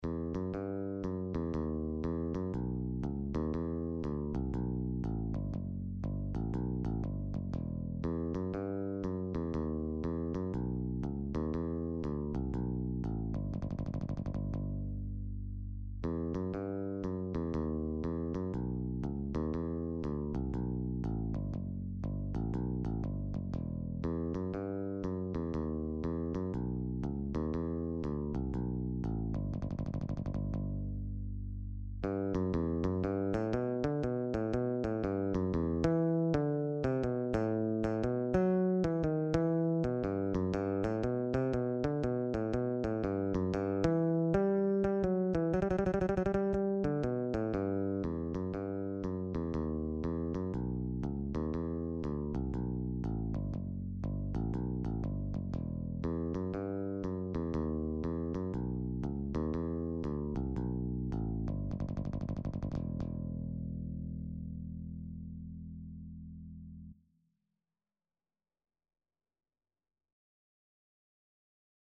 Quick Swing = c. 120
4/4 (View more 4/4 Music)
Bass Guitar  (View more Intermediate Bass Guitar Music)
Jazz (View more Jazz Bass Guitar Music)
Rock and pop (View more Rock and pop Bass Guitar Music)